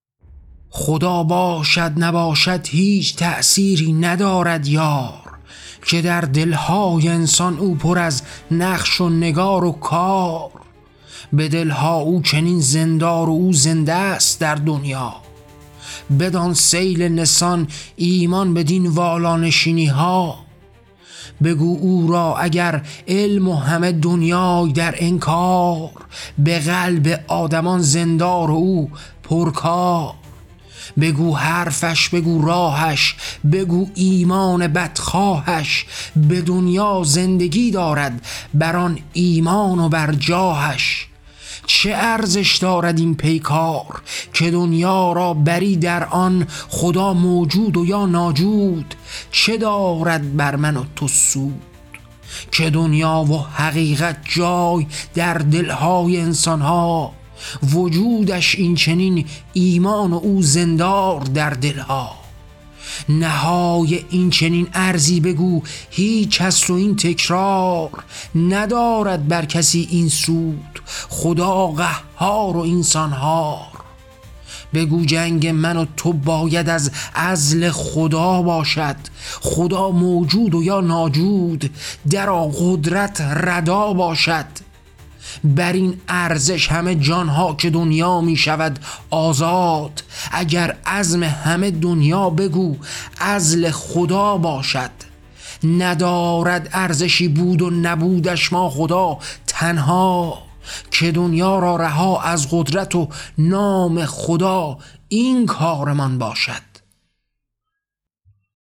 کتاب طغیان؛ شعرهای صوتی؛ بود، نبود: طغیان علیه استثمار از طریق نام و ردای خدا